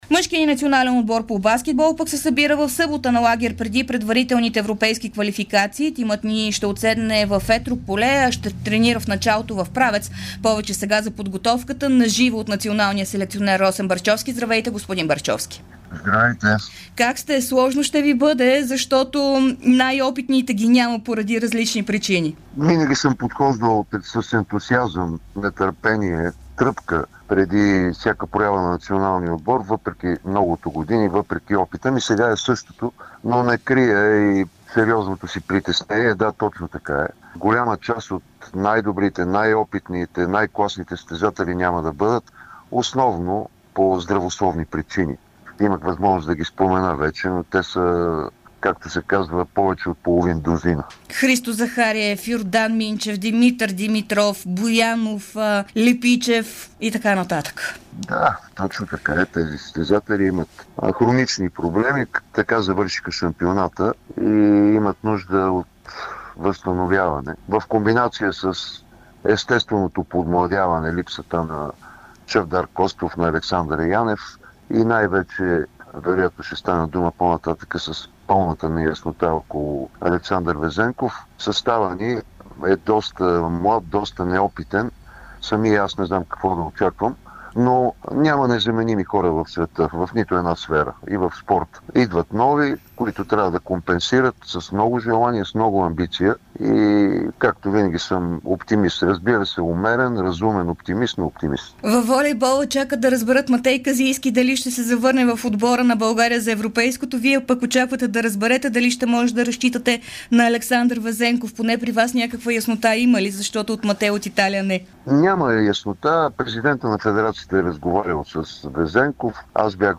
Националният селекционер на България Росен Барчовски даде специално интервю за Дарик радио и dsport преди началото на подготовката за предварителните европейски квалификации.